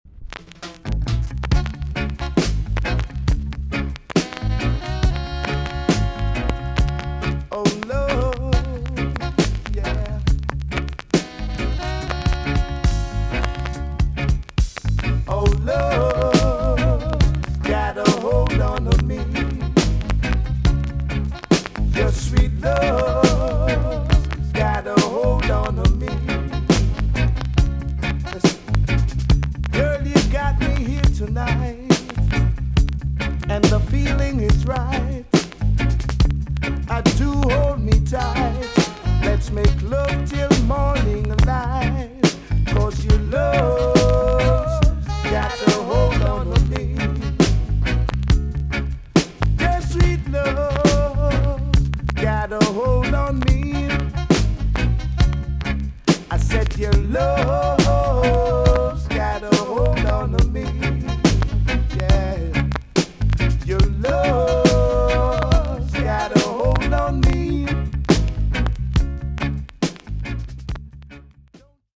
TOP >REGGAE & ROOTS
VG+~EX- 前半に軽いチリノイズが入ります。